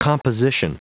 Prononciation du mot composition en anglais (fichier audio)
Prononciation du mot : composition